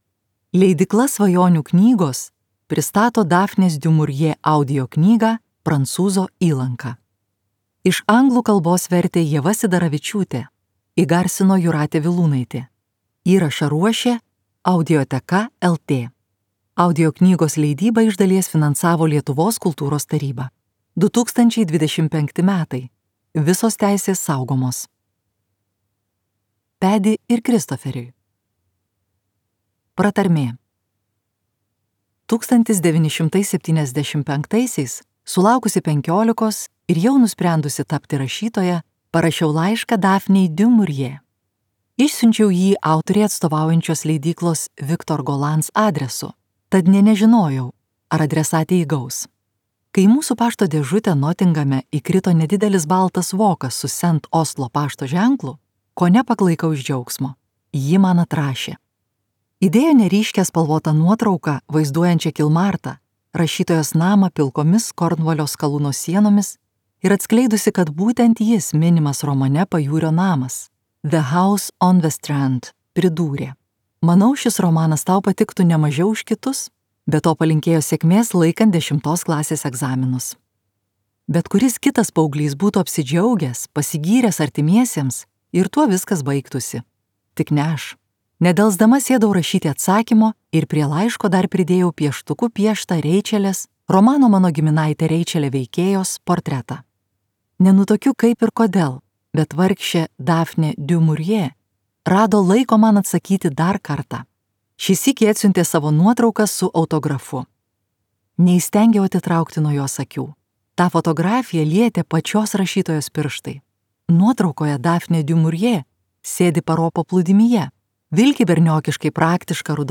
Daphne du Maurier audioknyga „Prancūzo įlanka“ – tai istorinis romanas, nukeliantis į XVII a. Kornvalį. Knygos herojė Dona Sent Kalum pabėga nuo Londono aukštuomenės gyvenimo ir atranda laisvę, pavojų bei aistrą su paslaptingu prancūzų piratu.